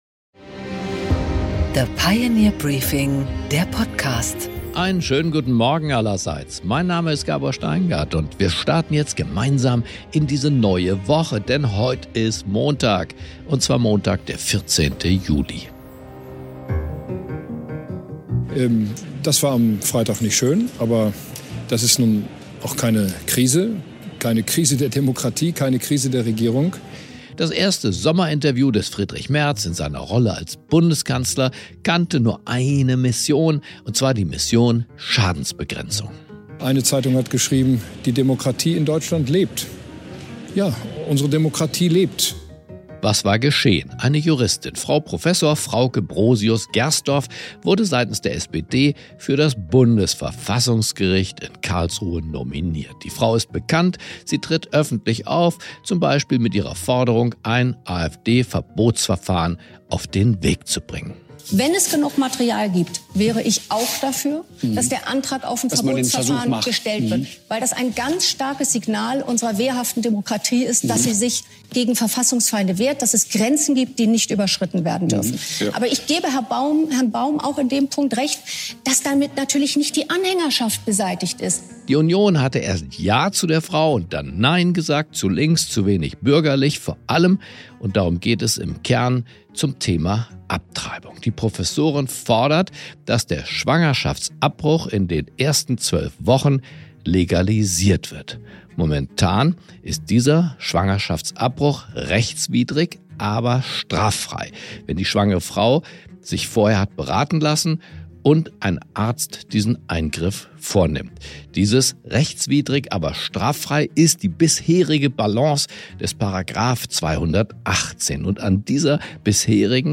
Gabor Steingart präsentiert das Pioneer Briefing